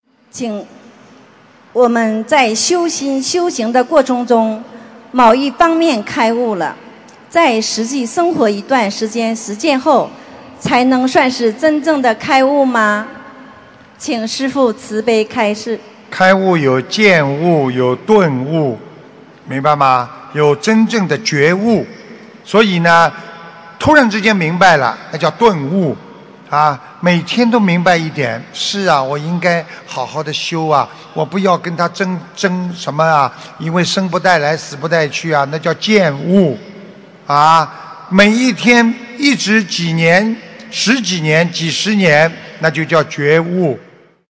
关于渐悟、顿悟和觉悟┃弟子提问 师父回答 - 2017 - 心如菩提 - Powered by Discuz!